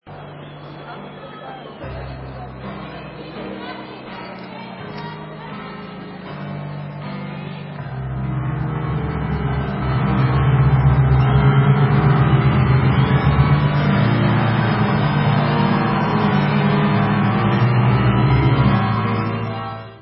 Live From Le Zenith